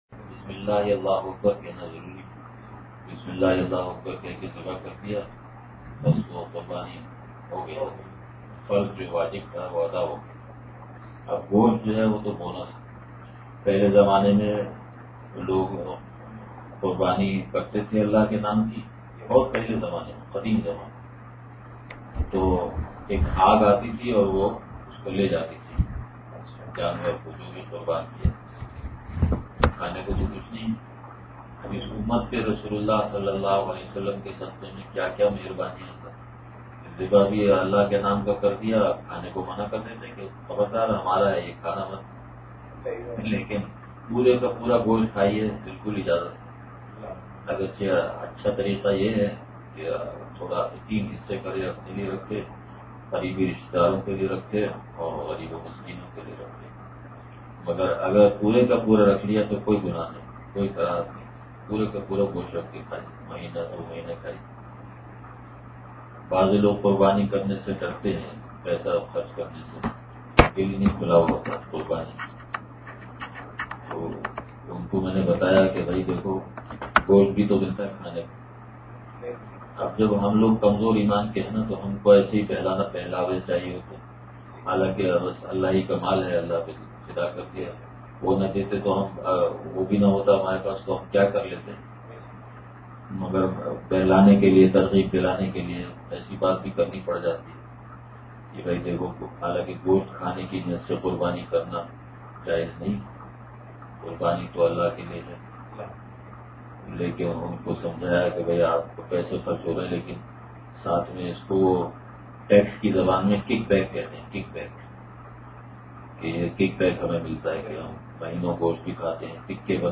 بیان- کوکن